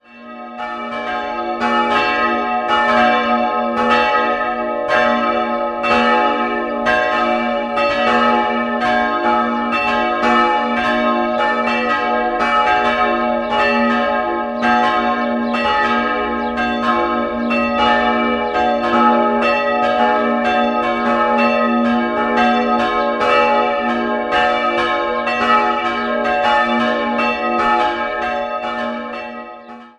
Der Unterbau des Turms blieb bestehen und wurde aufgestockt. 3-stimmiges As-Dur-Geläute: as'-c''-es'' Die Glocken wurden im Jahr 1922 vom Bochumer Verein für Gussstahlfabrikation gegossen.